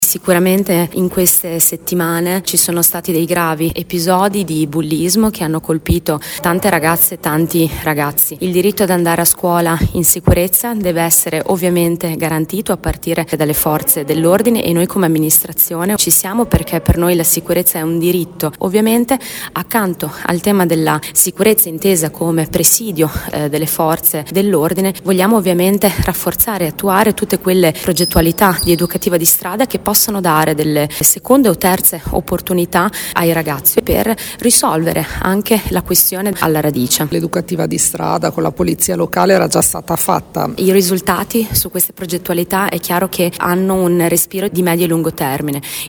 “Per noi la sicurezza è un diritto” ha detto Federica Venturelli, assessore alle politiche educative del comune: